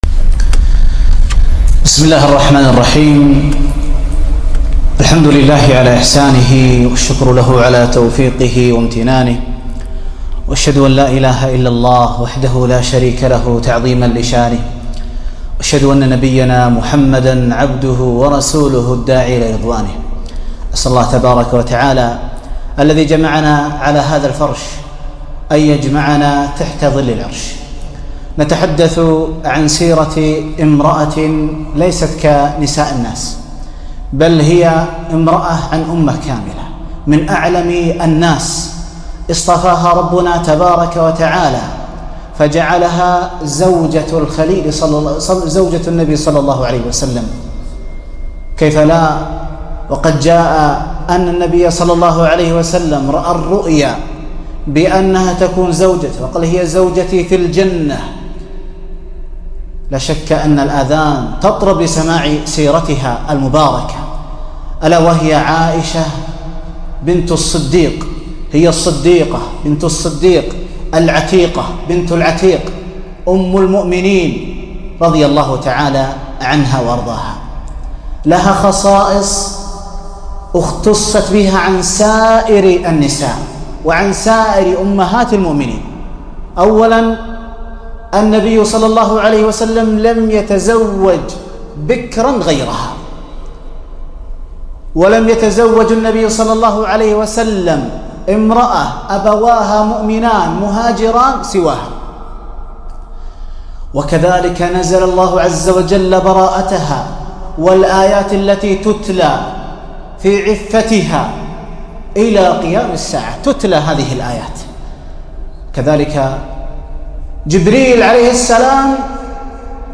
محاضرة - سيرة أم المؤمنين عائشة رضي الله عنها